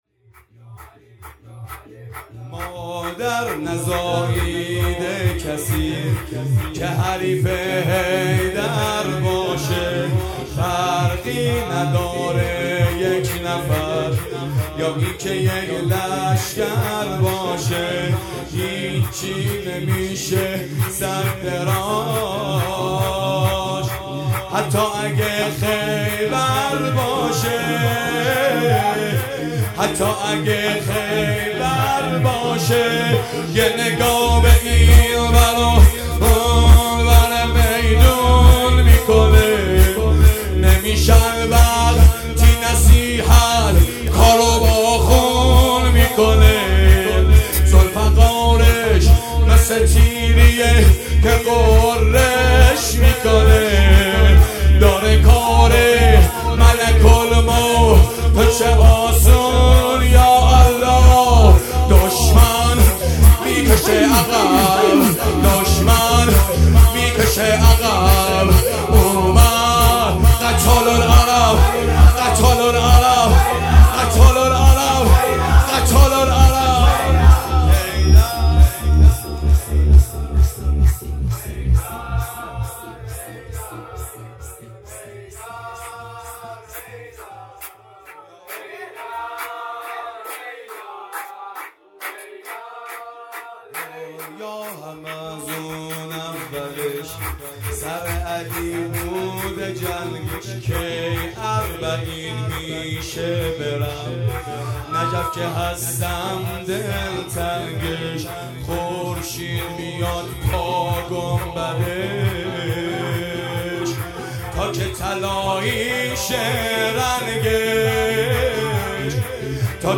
شور
ایام نیمه شعبان